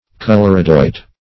Search Result for " coloradoite" : The Collaborative International Dictionary of English v.0.48: Coloradoite \Col`o*ra"do*ite\, n. (Min.) Mercury telluride, an iron-black metallic mineral, found in Colorado.
coloradoite.mp3